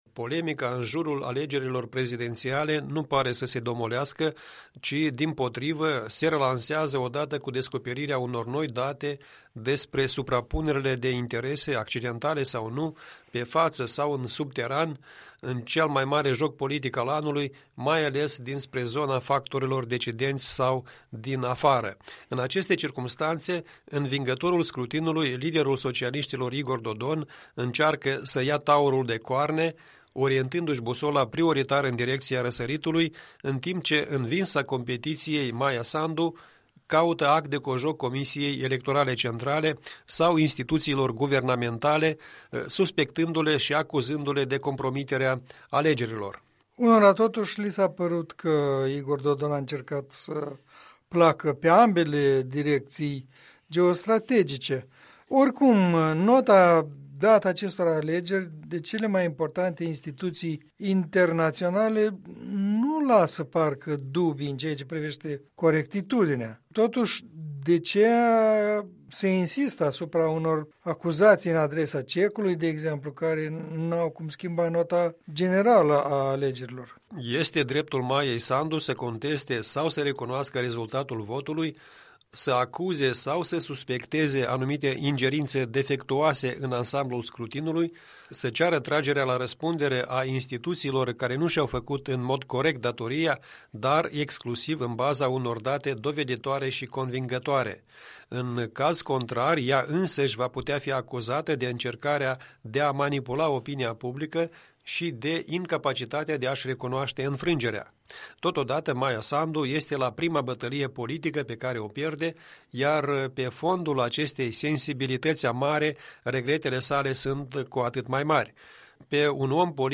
în dialog cu istoricul și publicistul